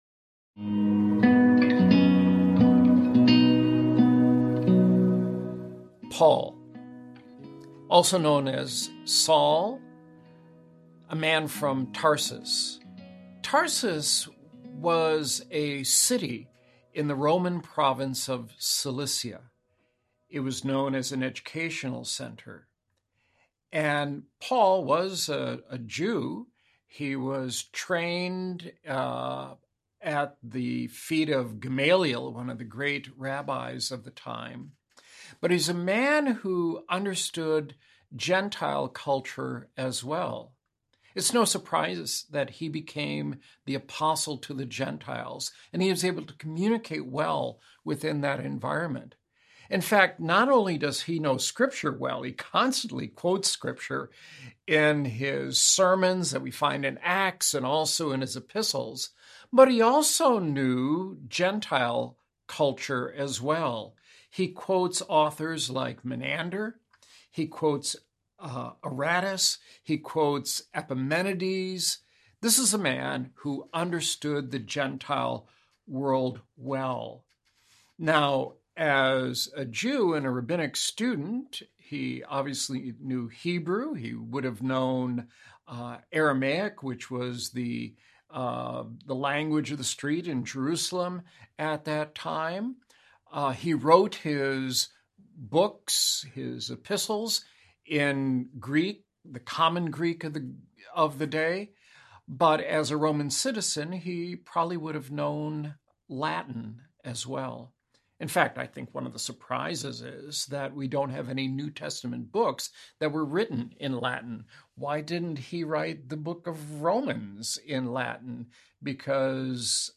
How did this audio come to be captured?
Unlike a traditional audiobook’s direct narration of a book’s text, The New Testament in Antiquity: Audio Lectures 2 includes high quality live-recordings of college-level lectures that cover the important points from each subject as well as relevant material from other sources.